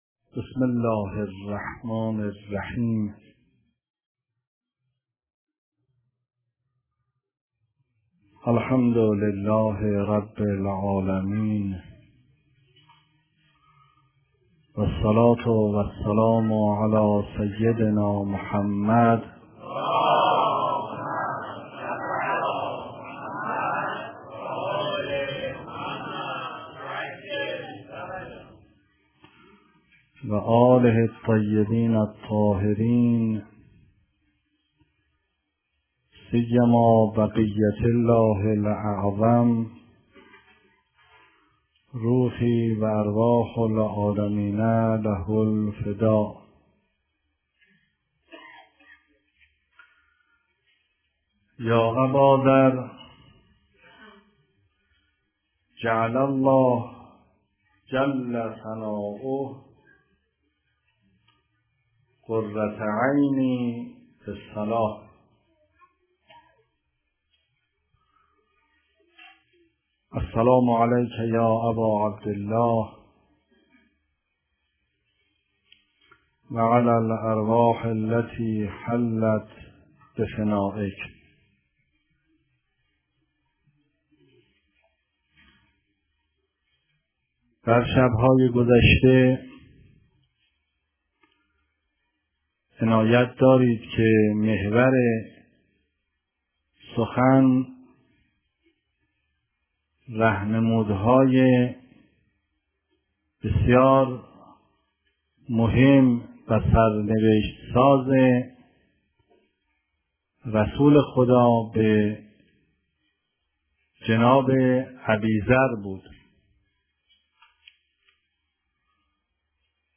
سخنرانی در آستان مقدس حضرت عبدالعظیم حسنی علیه السلام در تاریخ دهم محرم 1389